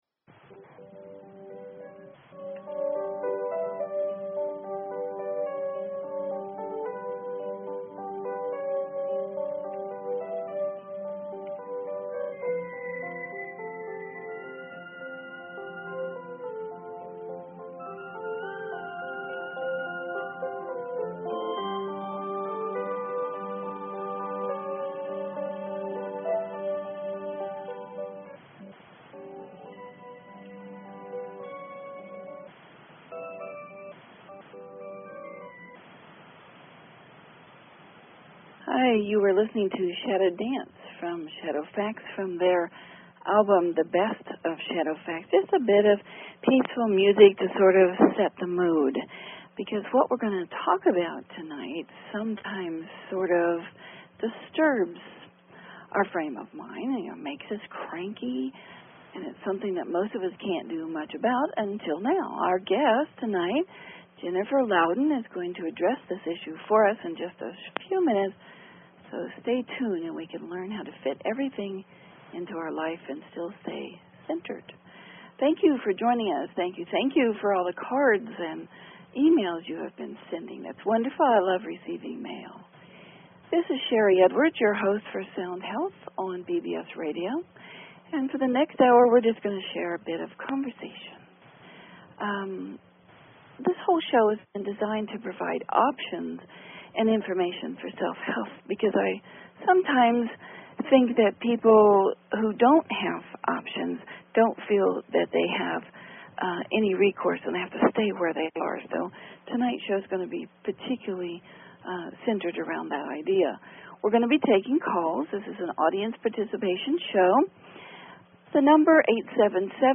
Talk Show Episode, Audio Podcast, Sound_Health and Courtesy of BBS Radio on , show guests , about , categorized as
A lot of the show was taken up with audience participation questions.